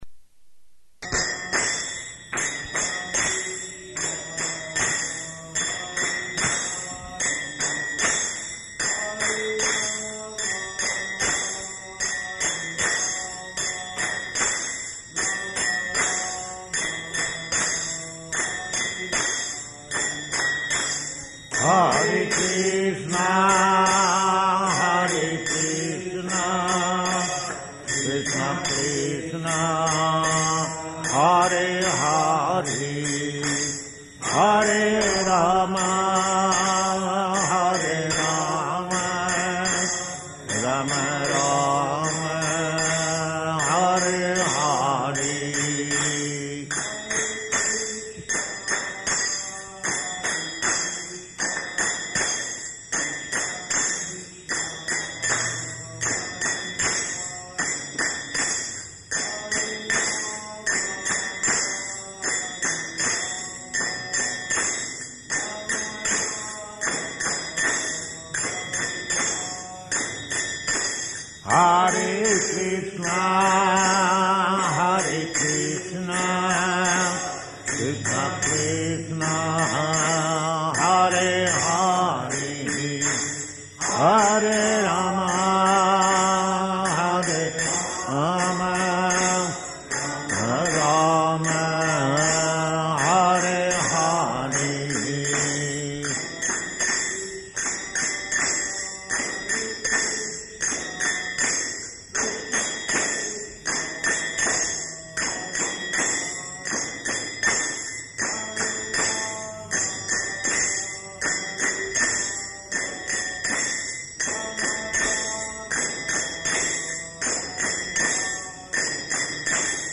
Bhagavad-gītā 2.40-45 --:-- --:-- Type: Bhagavad-gita Dated: December 13th 1968 Location: Los Angeles Audio file: 681213BG-LOS_ANGELES.mp3 Prabhupāda: [ kīrtana ] [ prema-dhvani ] Thank you very much.